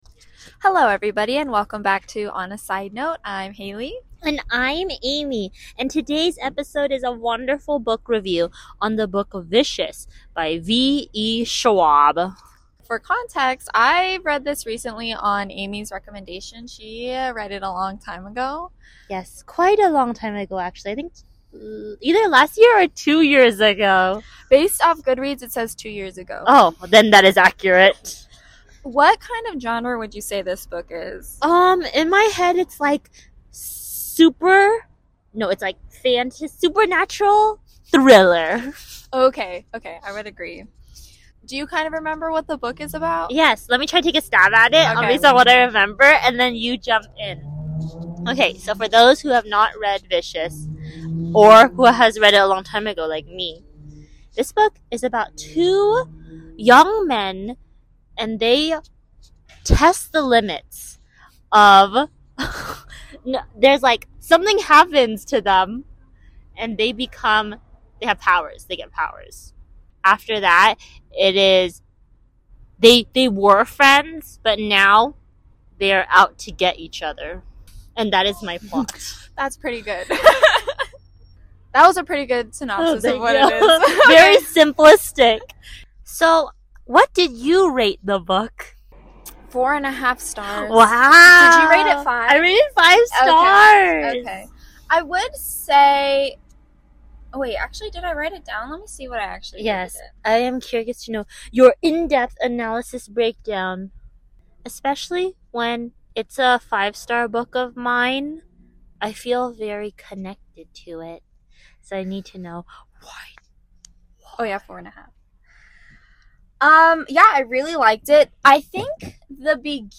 Book Review: Vicious